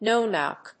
アクセントnó‐knòck